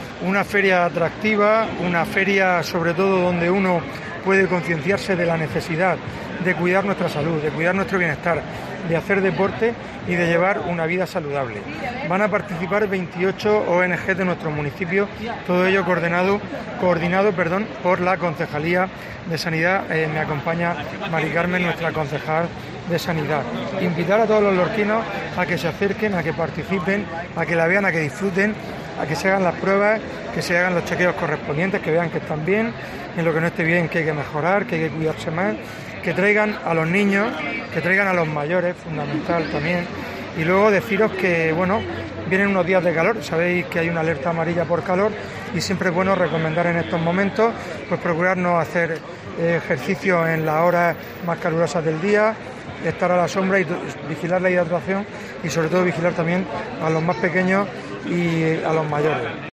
Fulgenco Gil, alcalde de Lorca sobre la feria de la salud